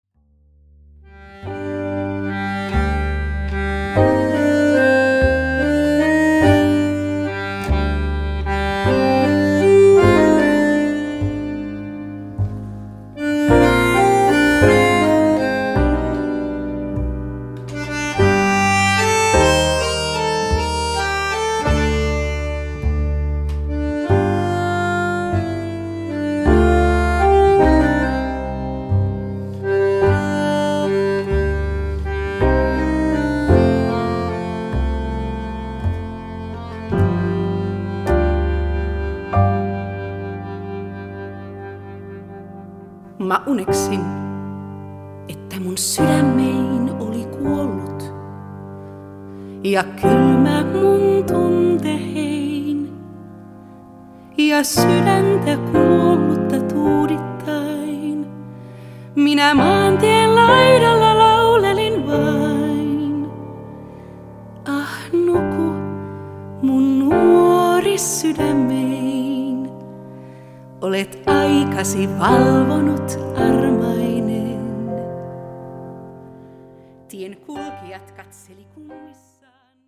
Studio LeRoy, Amsterdam, Holland